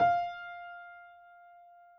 piano_065.wav